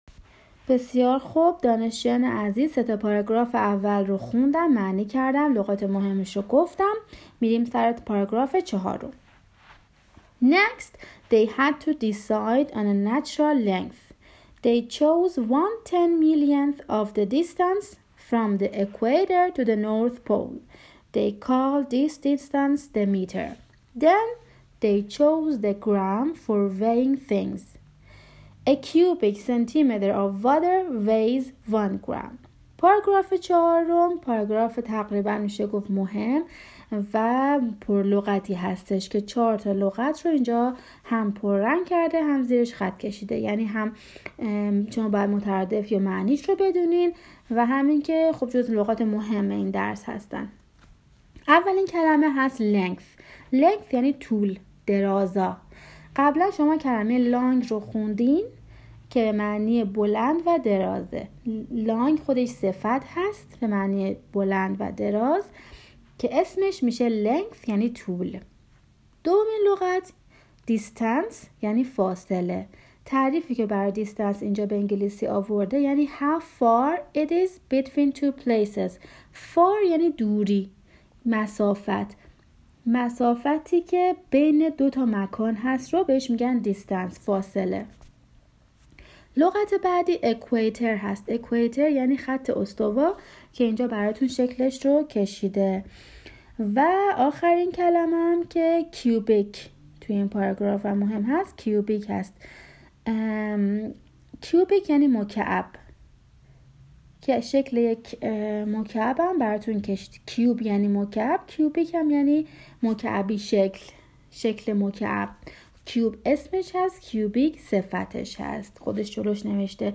تدریس درس 5 زبان عمومی قسمت دوم